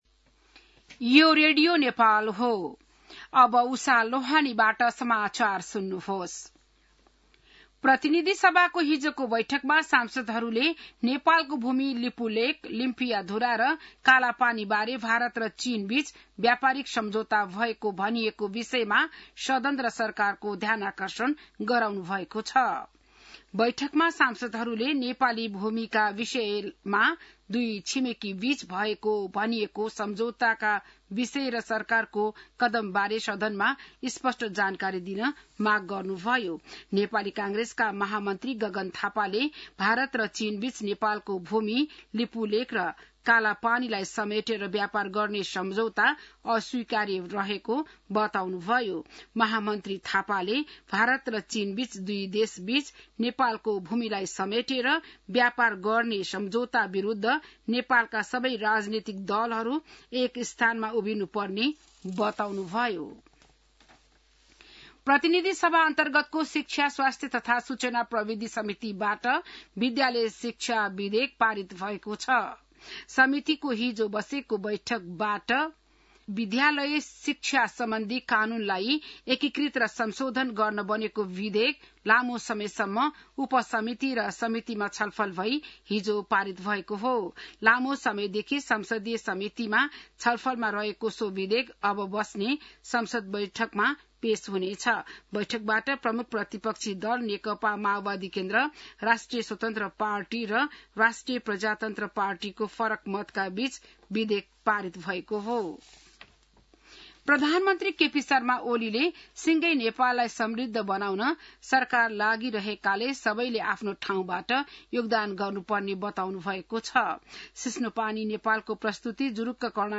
बिहान १० बजेको नेपाली समाचार : ६ भदौ , २०८२